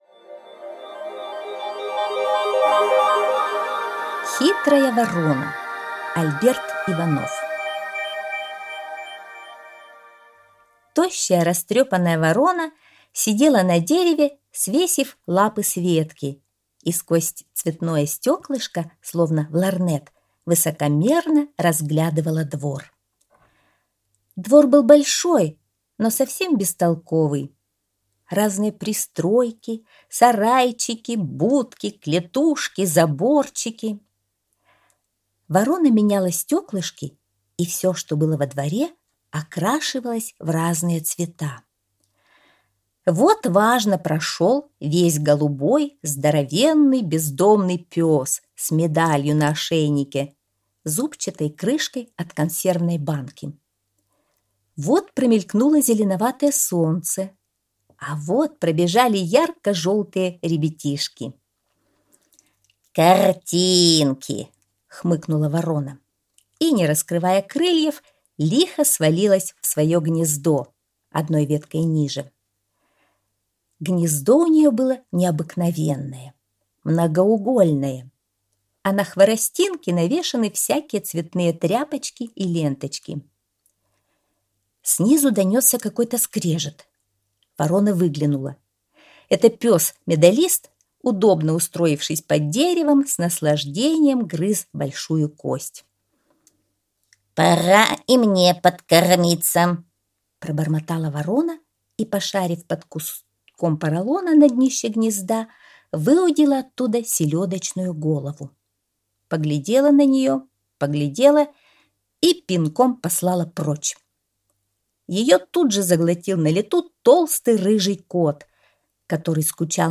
Хитрая ворона - аудиосказка Альберта Иванова - слушать онлайн